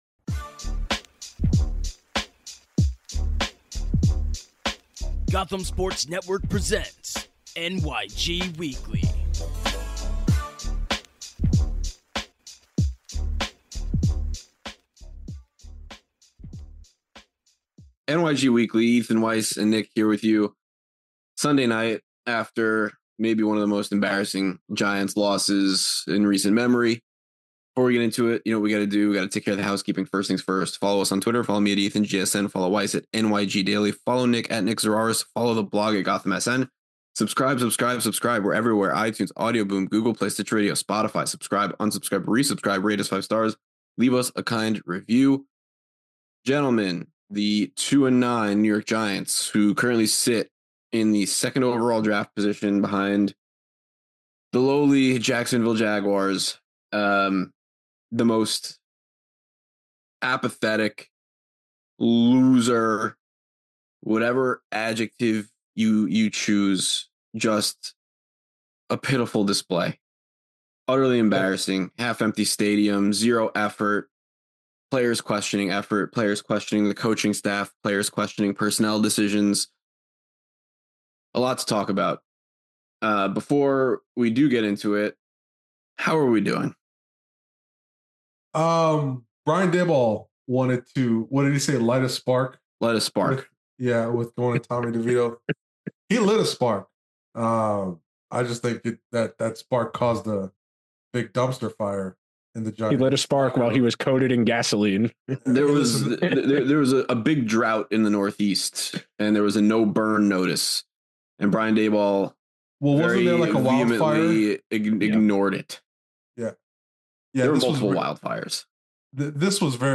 a wide ranging conversation